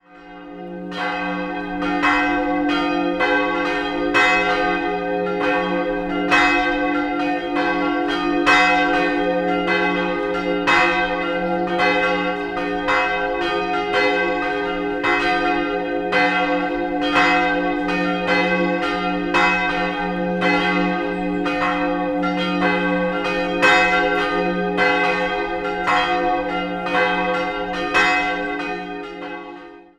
3-stimmiges Geläut: fis'-dis''-eis'' Die große Glocke ist die älteste Leipzigs und wurde Ende des 13. Jahrhunderts gegossen, die mittlere ist unbezeichnet und entstand in der ersten Hälfte des 15. Jahrhunderts und die kleine ist ein Werk von Hans Wetter und entstand 1625.